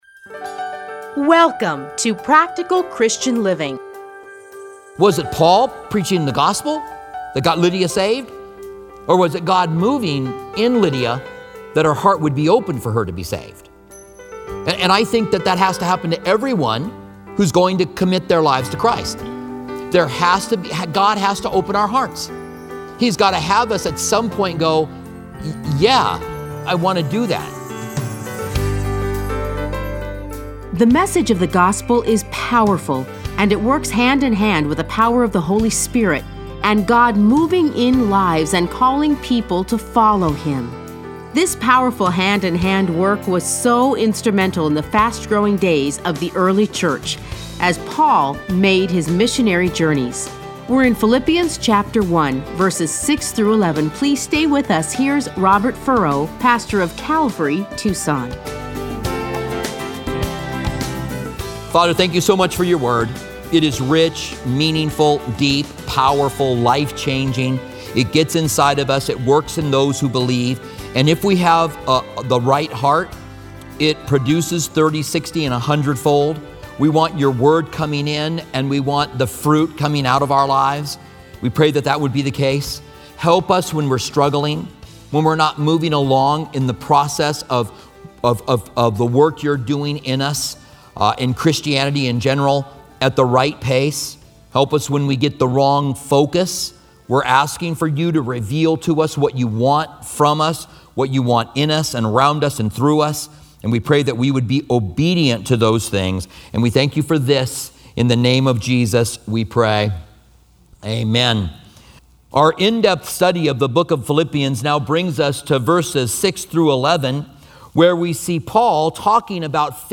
Listen to a teaching from Philippians 1:6-11 Playlists A Study in Philippians Download Audio